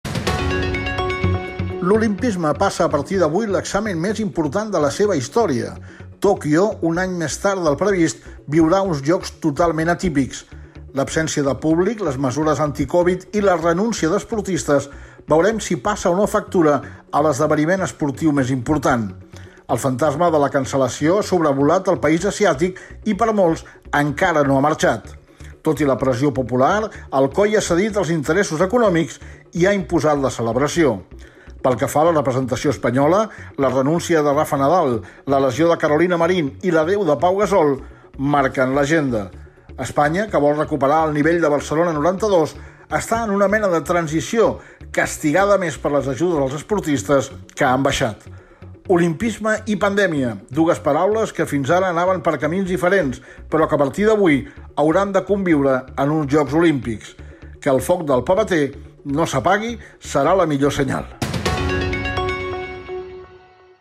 Article d'opinió sobre l'inici dels Jocs Olímpics de Tòquio
Esportiu
FM